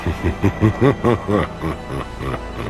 Hehehehe #3
Category: Television   Right: Both Personal and Commercial